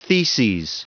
Prononciation du mot theses en anglais (fichier audio)
Prononciation du mot : theses